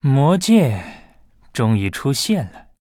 文件 文件历史 文件用途 全域文件用途 Bhan_fw_02.ogg （Ogg Vorbis声音文件，长度2.8秒，98 kbps，文件大小：34 KB） 源地址:地下城与勇士游戏语音 文件历史 点击某个日期/时间查看对应时刻的文件。